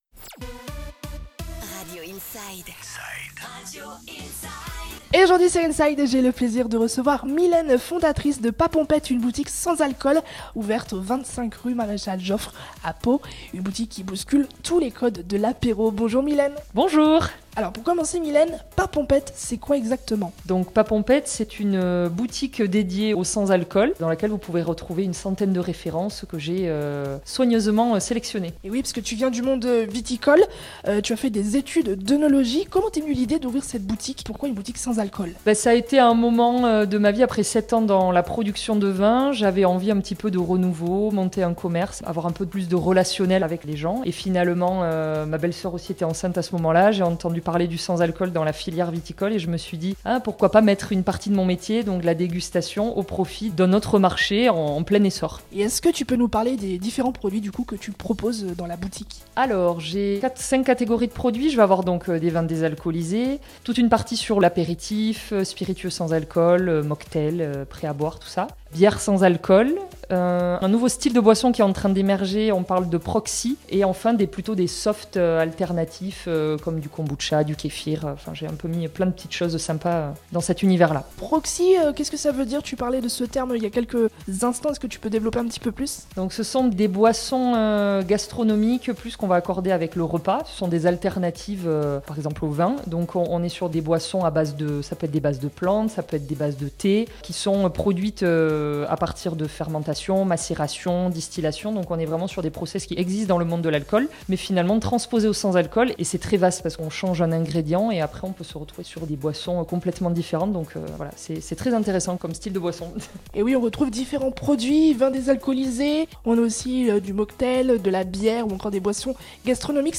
INSIDE : Réécoutez les flash infos et les différentes chroniques de votre radio⬦